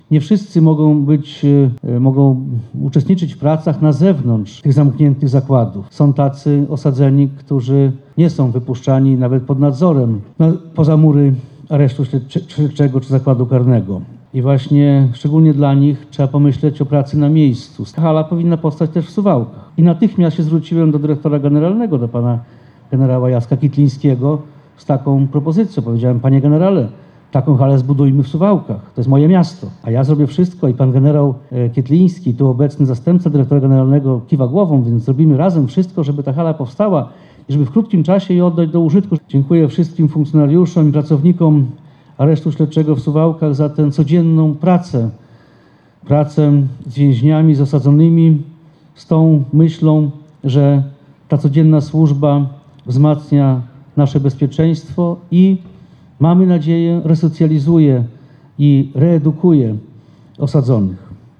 W sobotę (15.09) przy Kościele Świętych Apostołów Piotra i Pawła odbył się uroczysty apel oraz ceremonia nadania sztandaru suwalskiej placówce.
Po odczytaniu aktu nadania sztandaru oraz symbolicznym wbiciu gwoździ honorowych, głos zabrał między innymi poseł i wiceminister Jarosław Zieliński.